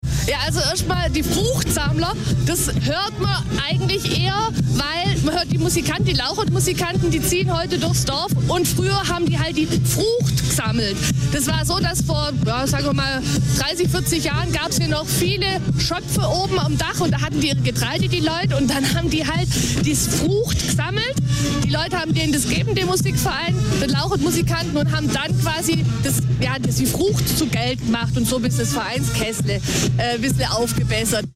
In Melchingen ziehen die Fruchtsammler von Haus zu Haus